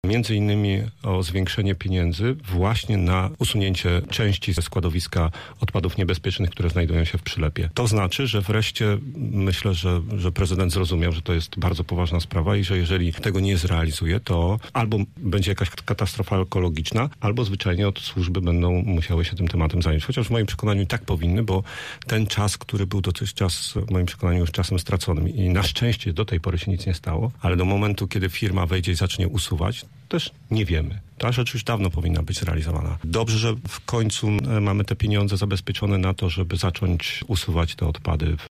Mówi Piotr Barczak przewodniczący klubu radnych PiS: